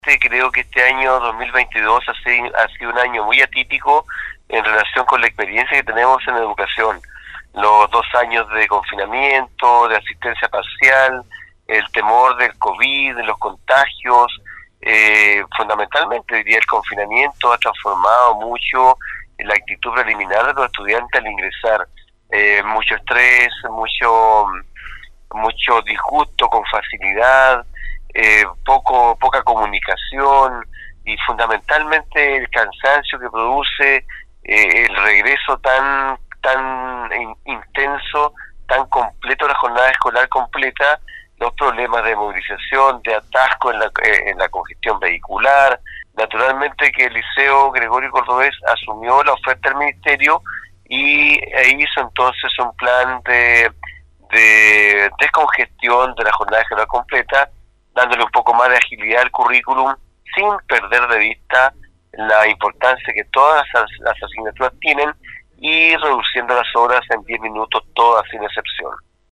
Conversamos con